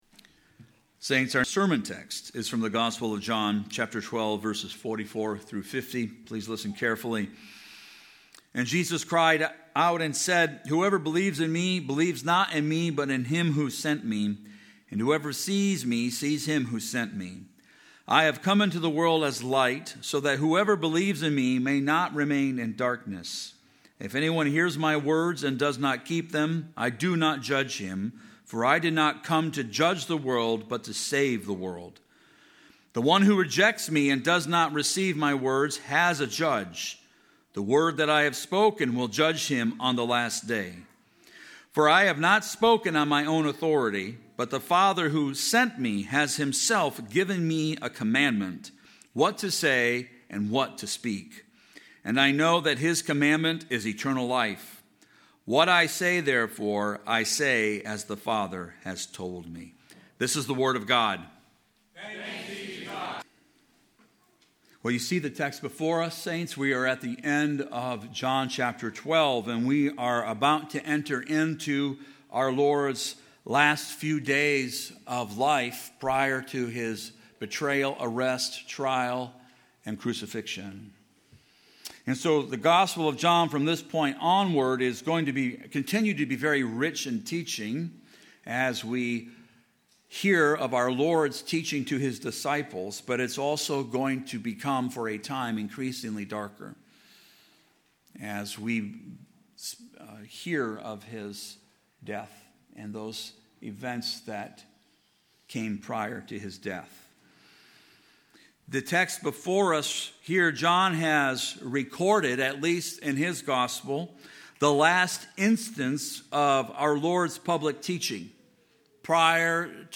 TRC_Sermon-4.12.26.mp3